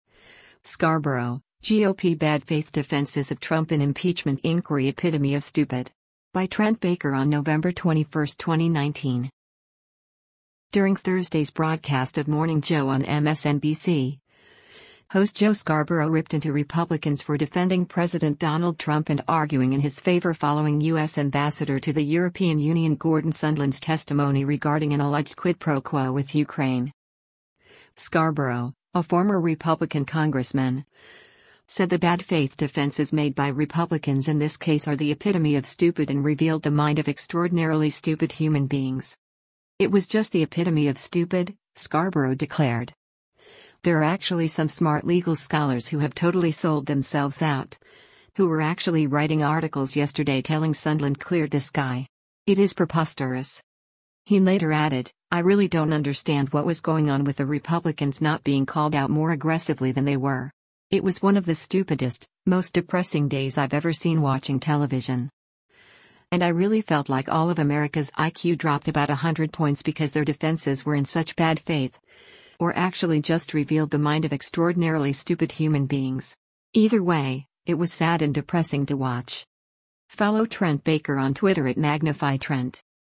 During Thursday’s broadcast of “Morning Joe” on MSNBC, host Joe Scarborough ripped into Republicans for defending President Donald Trump and arguing in his favor following U.S. Ambassador to the European Union Gordon Sondland’s testimony regarding an alleged quid pro quo with Ukraine.